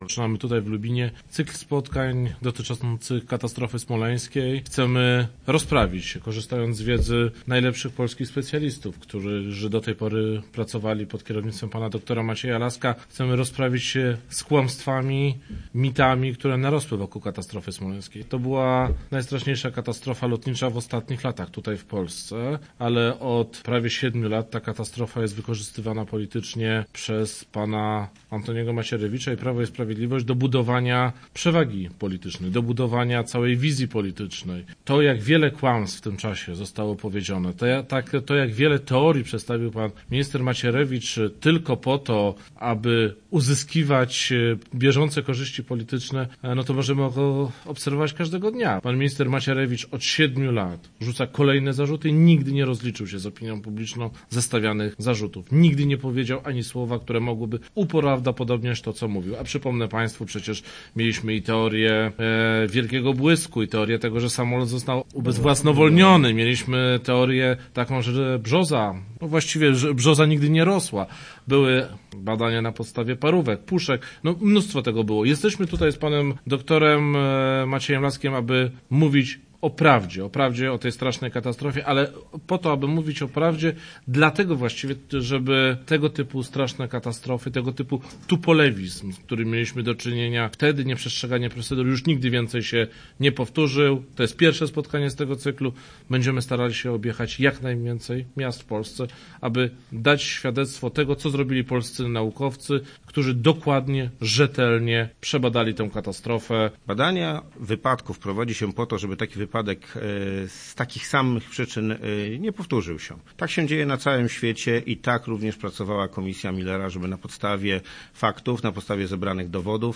W ramach Klubu Obywatelskiego rozmawiali o Smoleńsku
Lasek wraz z posłem Platformy Obywatelskiej, Marcinem Kierwińskim, byli gośćmi spotkania w ramach cyklicznych "Rozmów o Polsce" organizowanych przez Klub Obywatelski.
Poseł Marcin Kierwiński i dr Maciej Lasek: